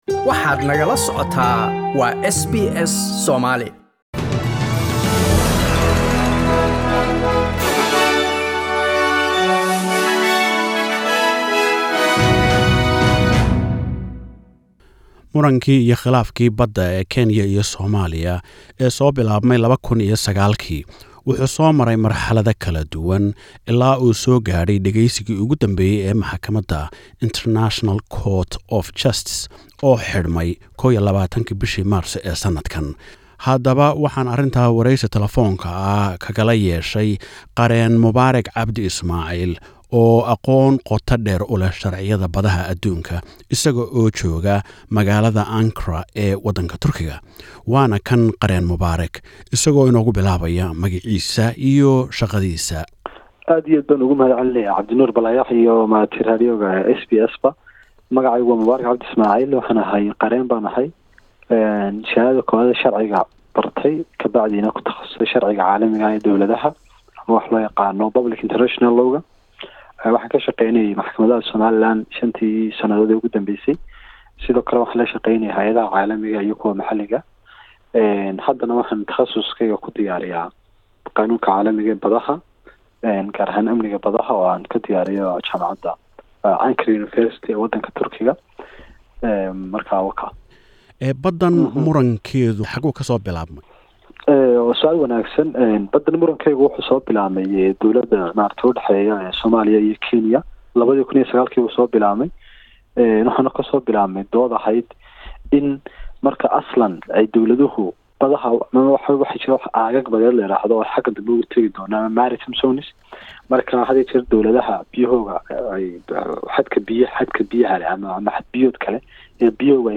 Waraysi Ku Saabsan Baddii iyo Go'aan Laga Filayo Maxakamadda ICJ